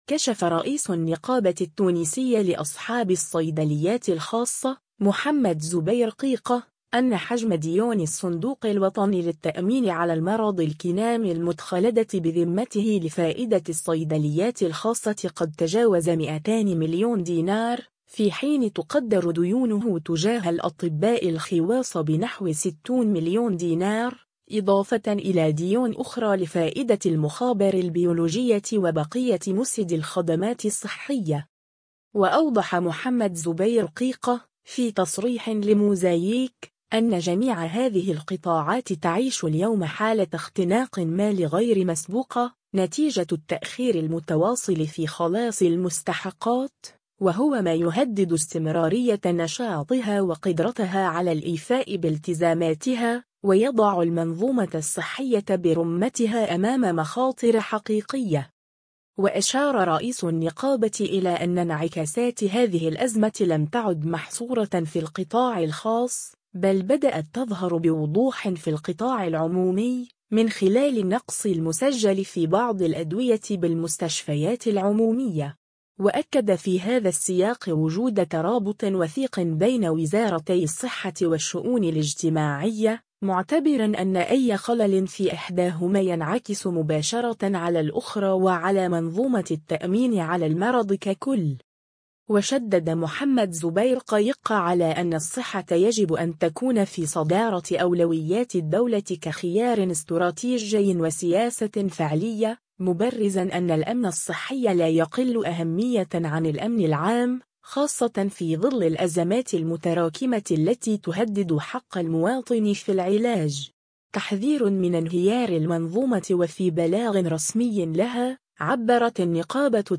في تصريح إذاعي